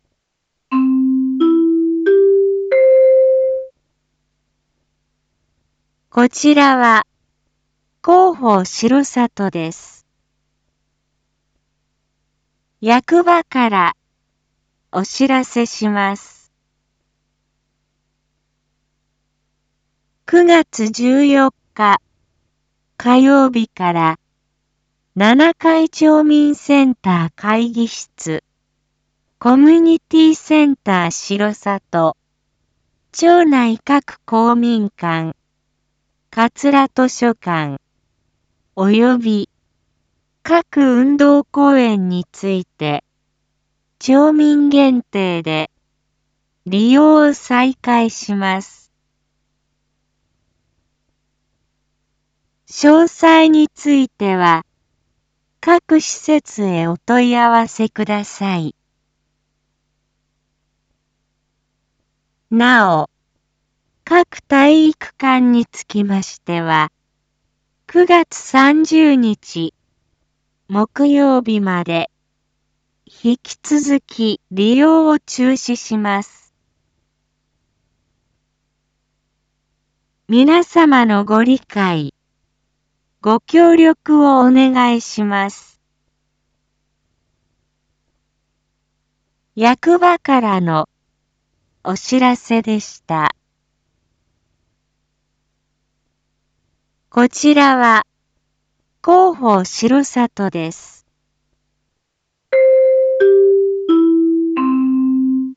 Back Home 一般放送情報 音声放送 再生 一般放送情報 登録日時：2021-09-10 19:01:52 タイトル：R3.9.10 19時放送 インフォメーション：こちらは、広報しろさとです。